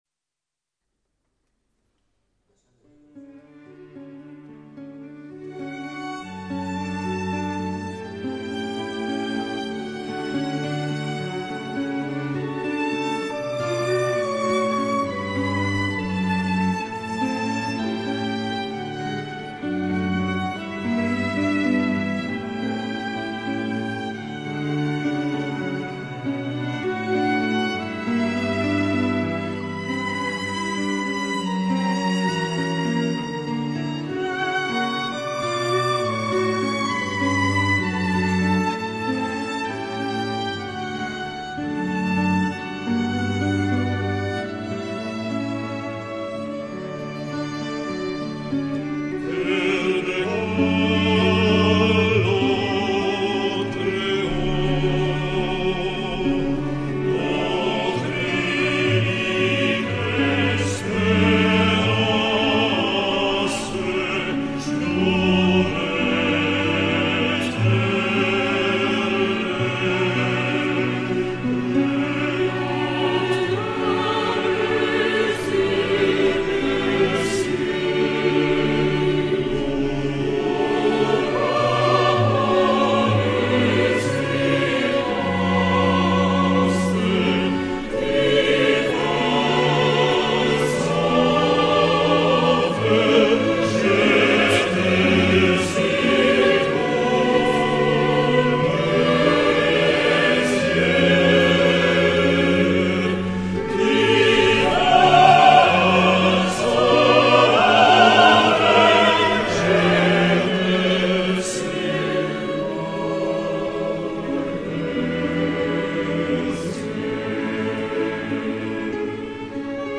enregistrement en l'église de Chinchon ( Madrid), choeur de 16 voix et 9 instruments
Cantique_de_Jean_Racine-iglesia_de_chinchon-22Khz-mono.mp3